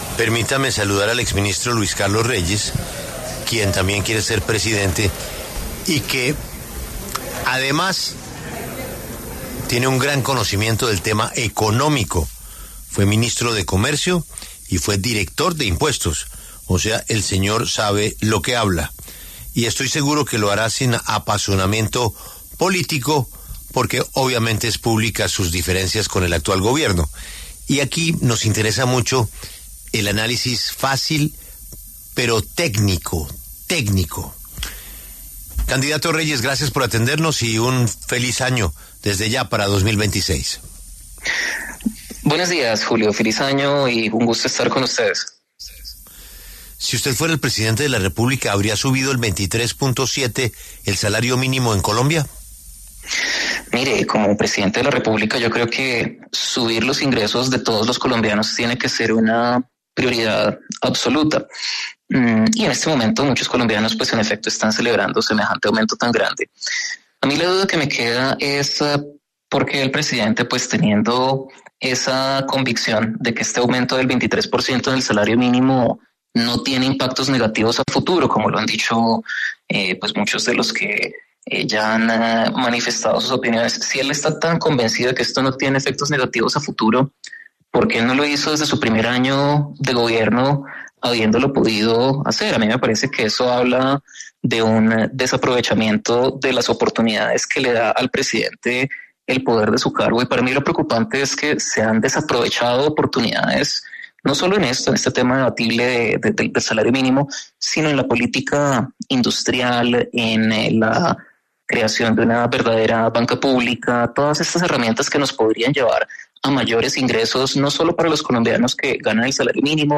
El exministro y exdirector de la Dian, Luis Carlos Reyes, habló en los micrófonos de La W sobre el incremento del salario mínimo para 2026 fijado en 2′000.000 con subsidio de transporte.